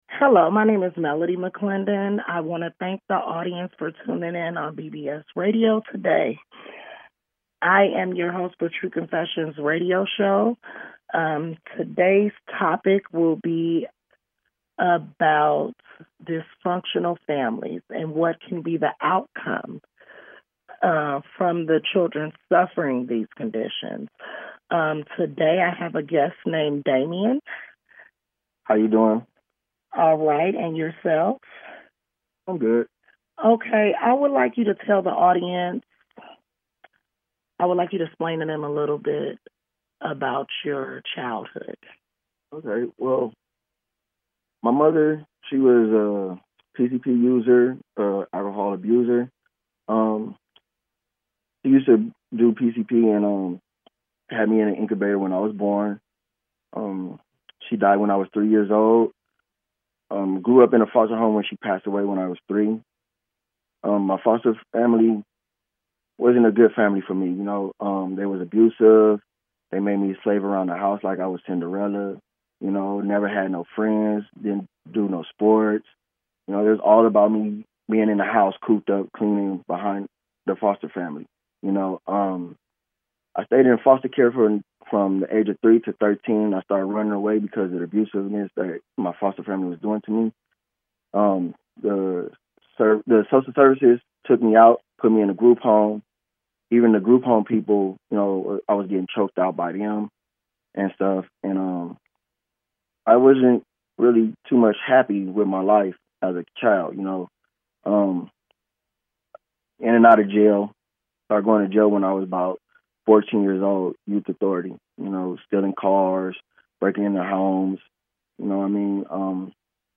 Talk Show Episode
We will interview pastors, gang members and people with a history of negative experiences.